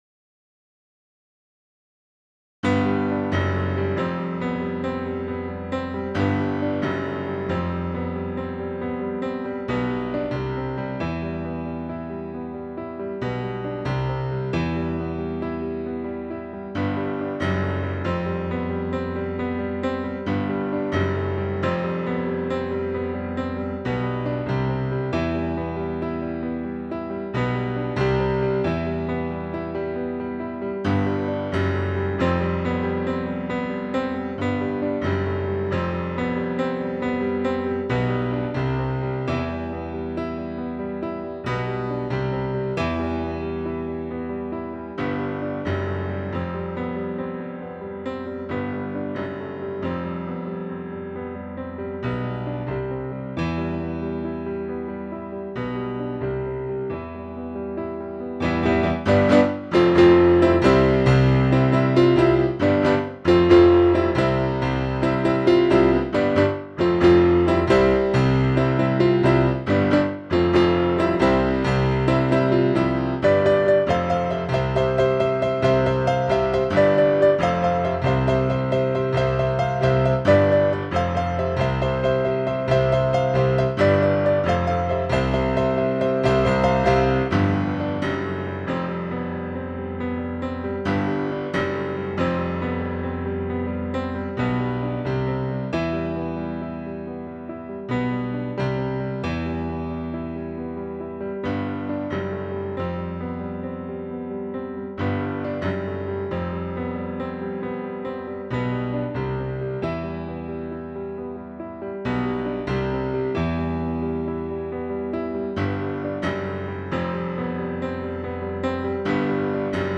Stienway Piano (48000 Hz).wav